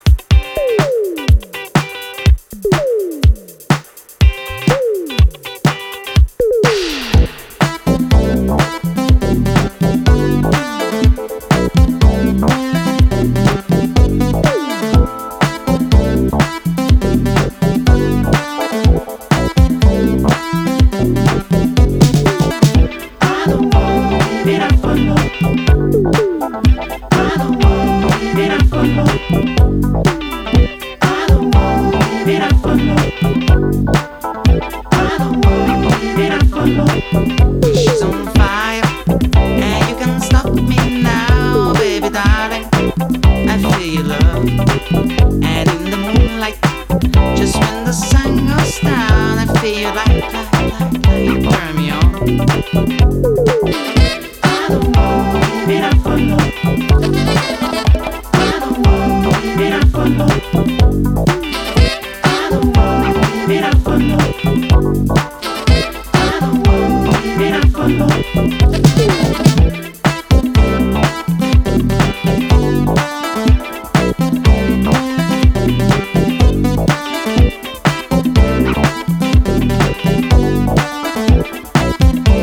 湿度高く親しみやすい陽性のサウンドが光る